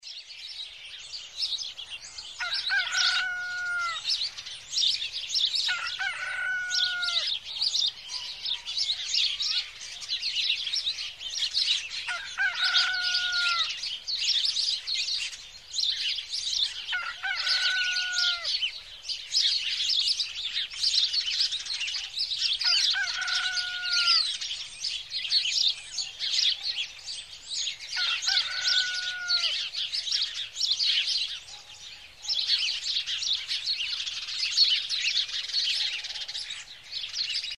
Звуки петуха
На этой странице представлена коллекция звуков петуха: от классического «кукареку» до заливистых многоголосых криков.